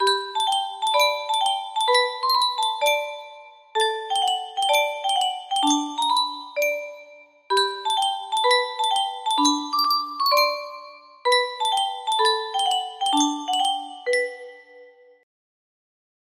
Yunsheng Spieluhr - Willi Ostermann OWWDFSDIC 3612 music box melody
Full range 60